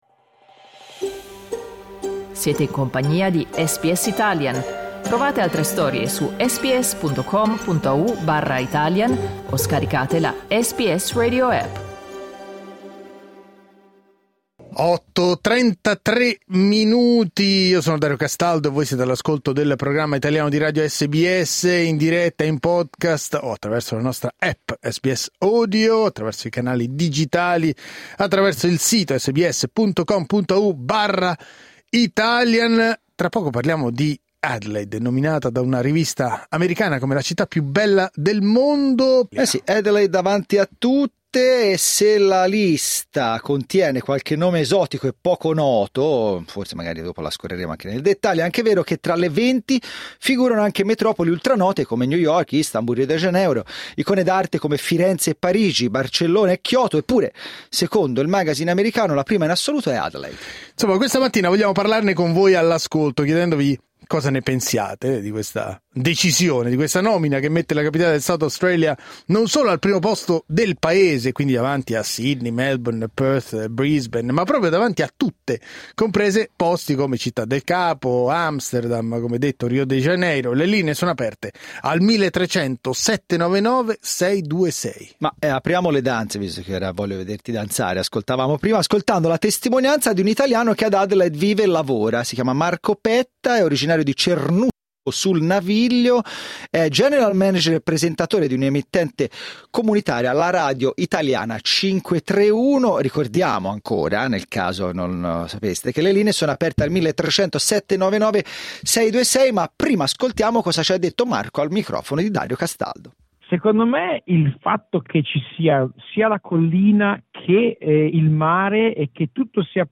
La classifica ha alimentato l'orgoglio della politica e della società civile del SA, e ha alimentato un dibattito nel quale abbiamo coinvolto gli ascoltatori di SBS Italian.